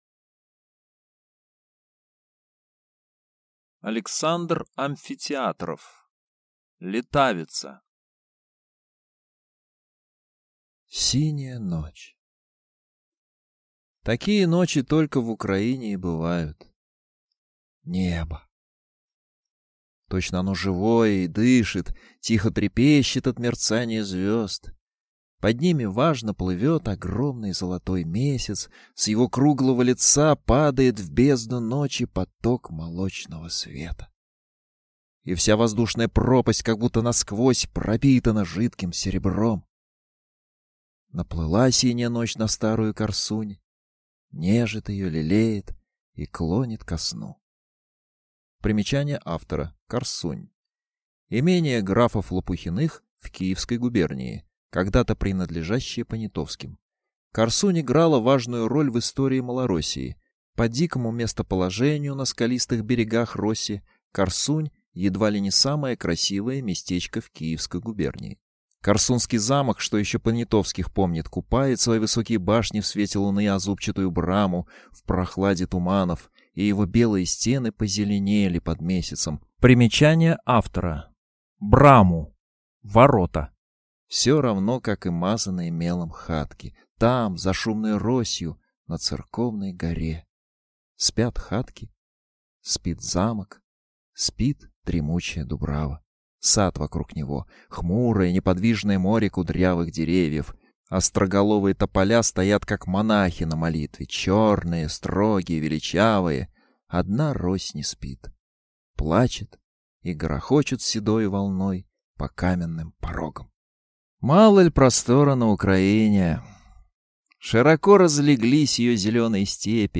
Аудиокнига Летавица | Библиотека аудиокниг
Прослушать и бесплатно скачать фрагмент аудиокниги